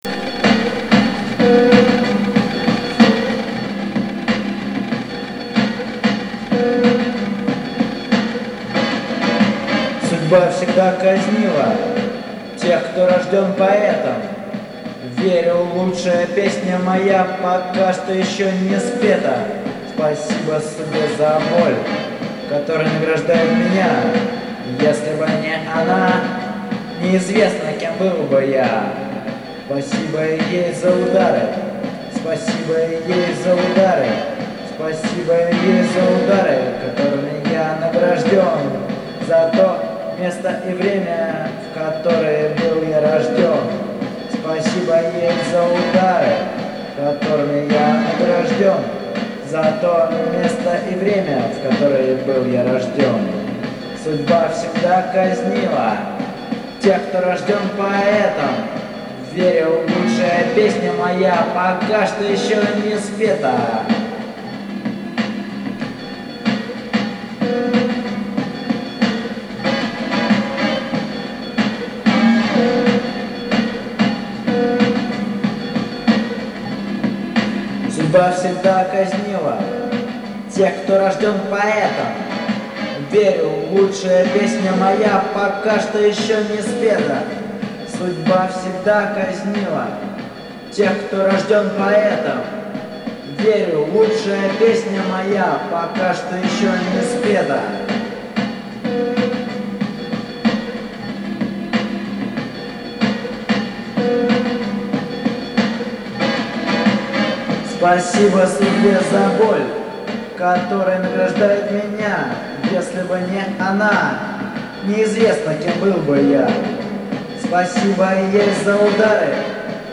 домашней студии